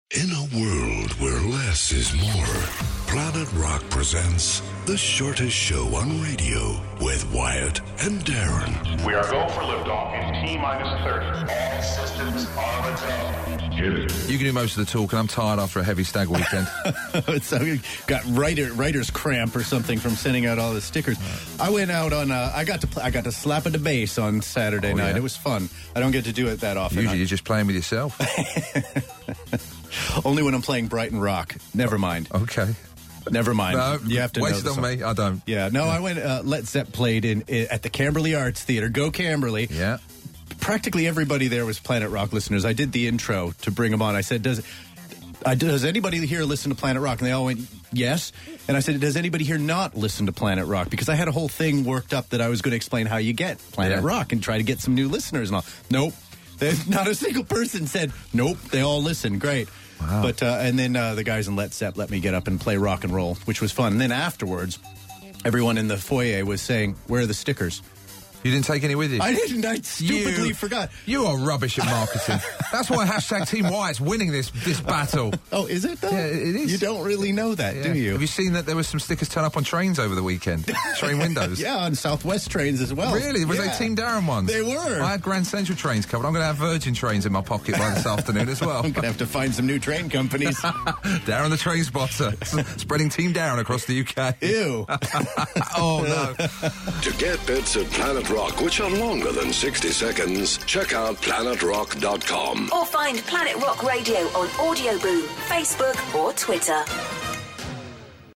bass slapping antics.